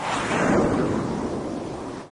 missilesLaunch.wav